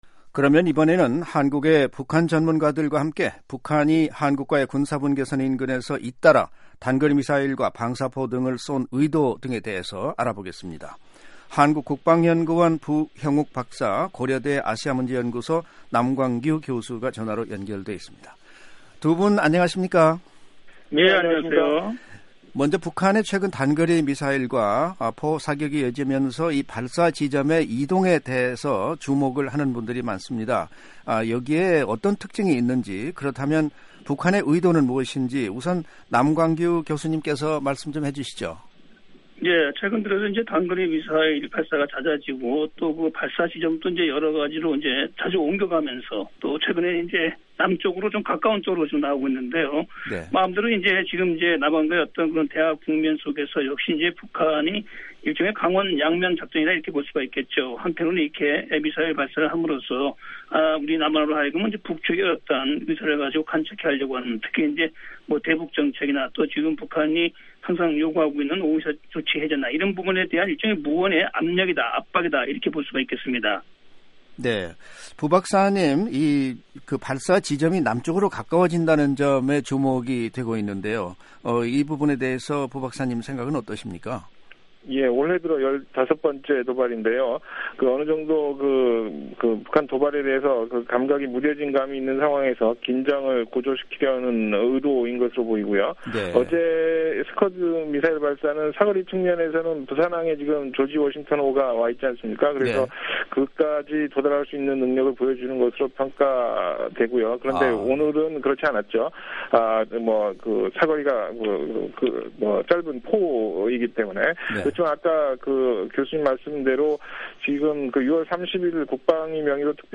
[인터뷰 오디오 듣기] 북한 잇단 사격 도발 의도